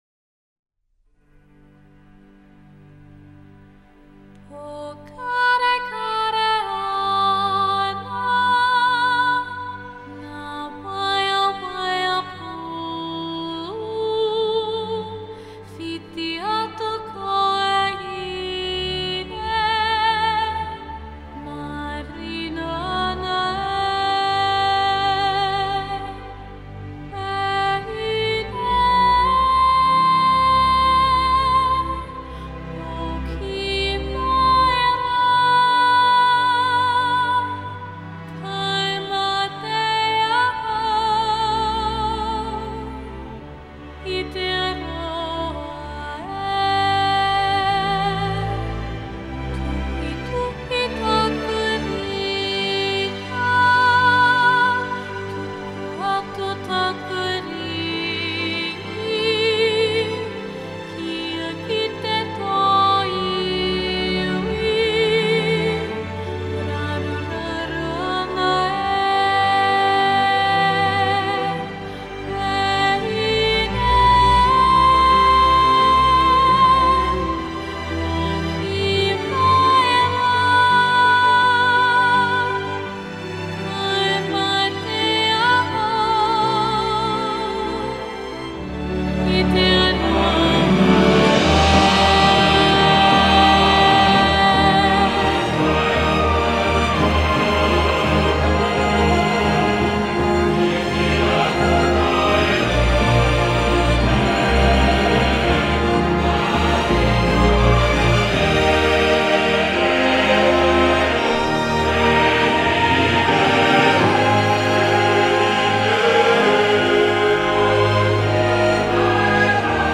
充满曼陀铃和竖琴声音的歌曲当中透显出一个宁静而美丽的少女形象